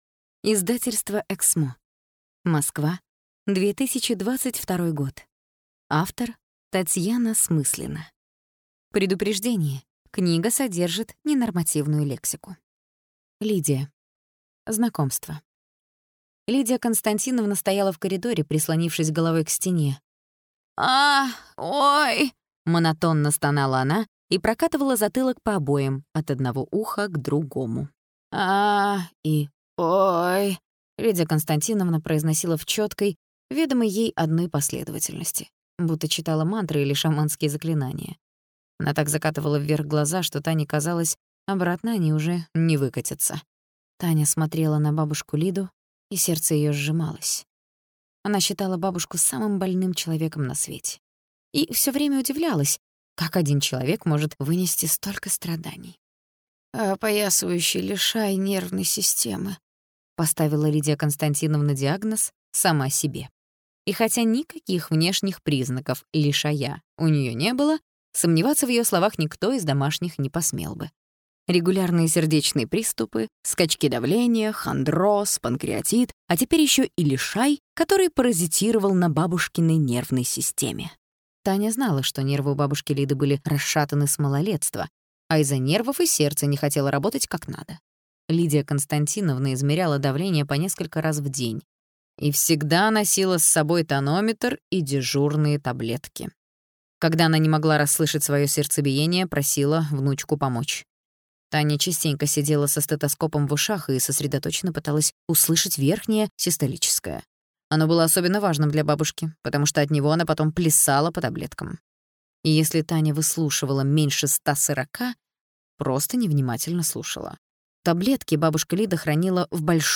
Аудиокнига Лидия | Библиотека аудиокниг